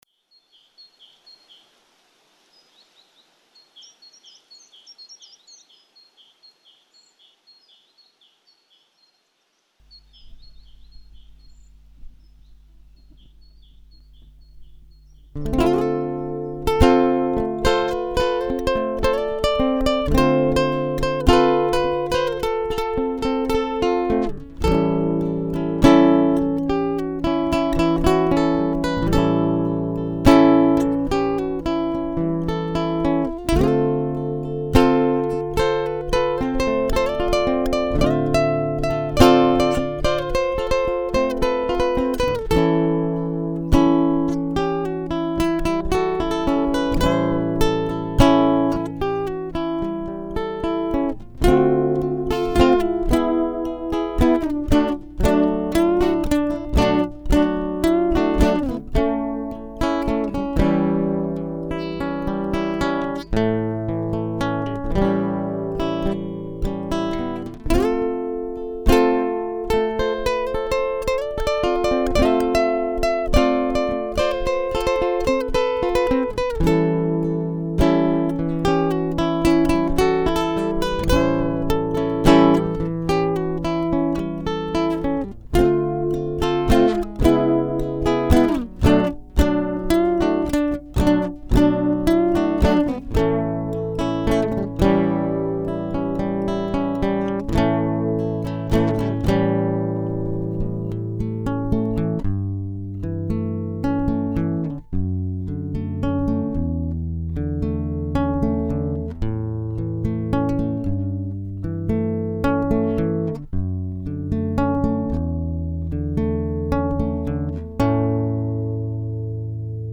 La grabacion fue hecha con una pendrive, por lo que el sonido bajo ningun punto de vista es optimo, es puro sentimiento en bruto.
Todos los temas estan grabados a duo con el bajista, guitarrista y cantante